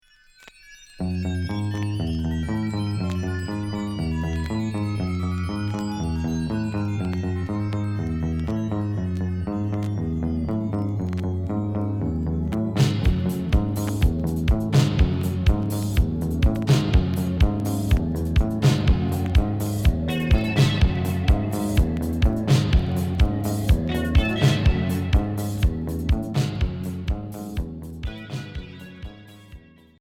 Cold wave